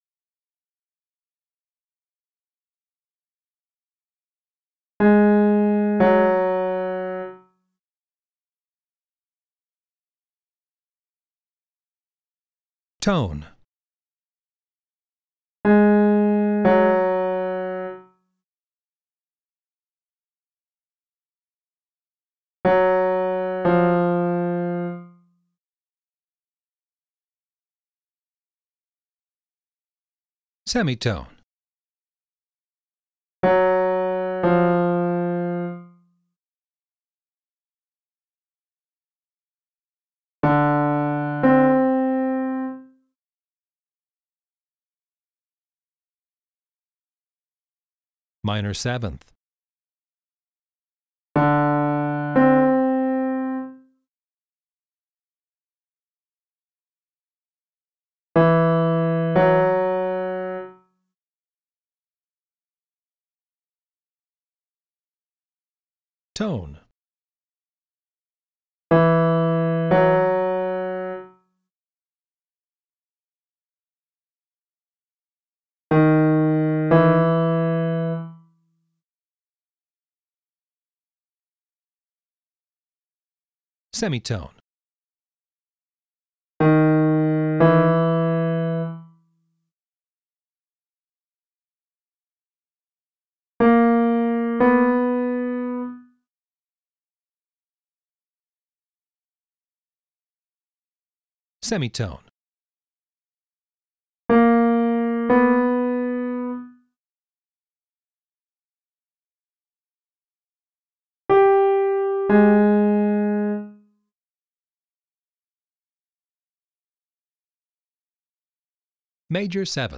This module lets you practice the intervals in each of the three forms and also in combinations of the forms: “melodic” includes ascending and descending, and “mixed” includes all three.
Once you think you’re getting a sense of each interval’s sound, listen to the corresponding “Test” tracks, which include a short pause after each interval.
You’ll hear the correct answer so you know if you got it right and have the chance to hear the interval again.
Test 3. Melodic
Test-3.-STTm7M7-melodic.mp3